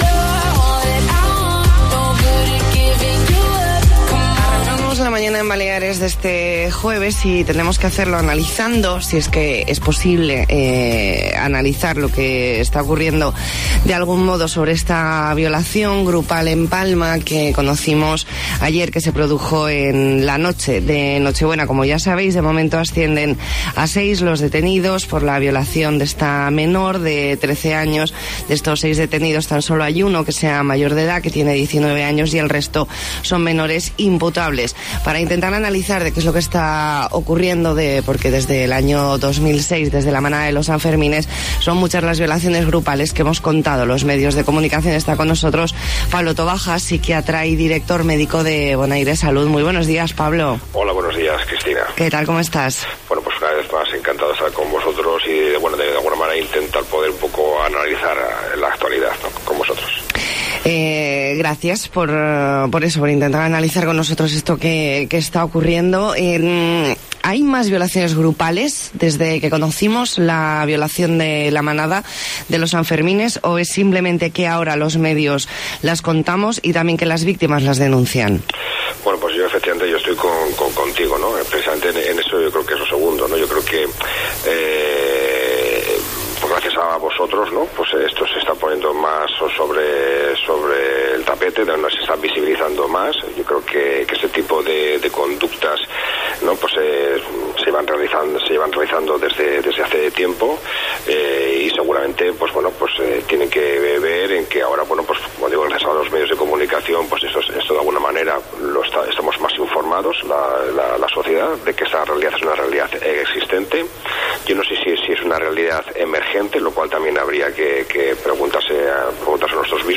Entrevista en La Mañana en COPE Más Mallorca, jueves 9 de enero de 2020.